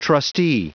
Prononciation du mot trustee en anglais (fichier audio)
Prononciation du mot : trustee